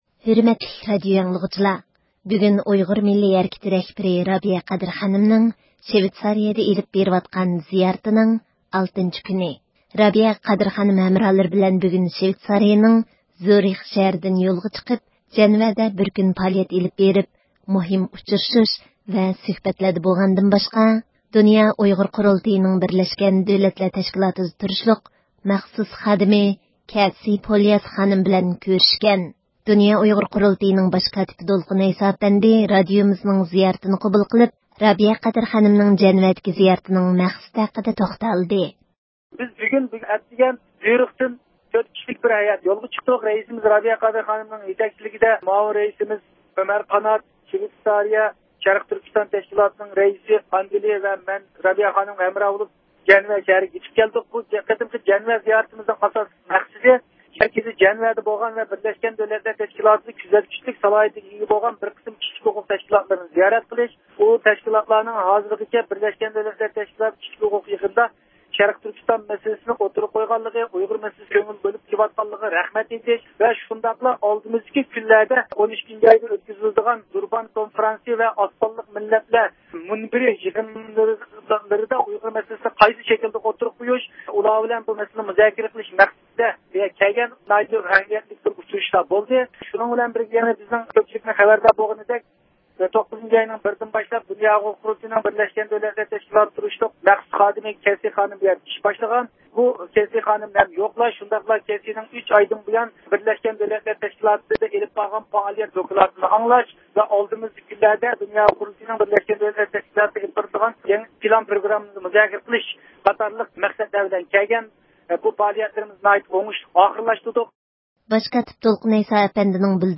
دۇنيا ئۇيغۇر قۇرۇلتىيىنىڭ باش كاتىپى دولقۇن ئەيسا ئەپەندى، رادىئومىزنىڭ زىيارىتىنى قوبۇل قىلىپ، رابىيە قادىر خانىمنىڭ جەنۋەدىكى زىيارىىتىنىڭ مەقسىتى ھەققىدە توختالدى.